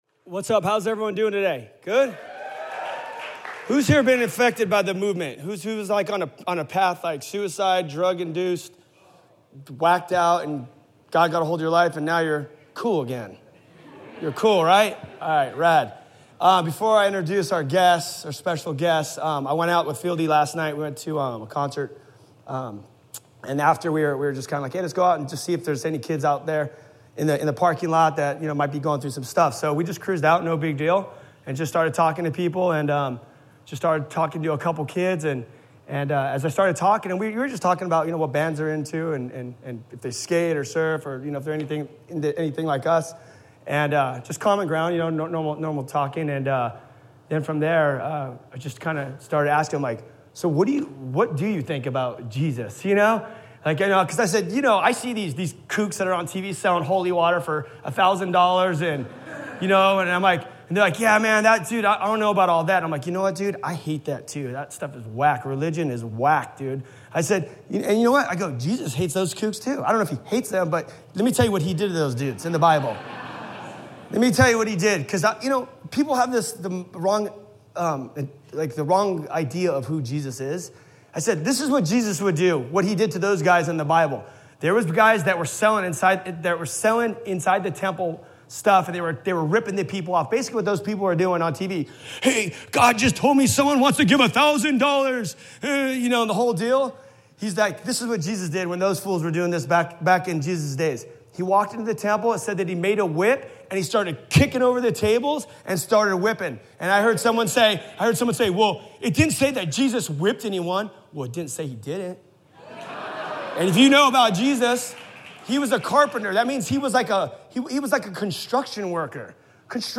Interview w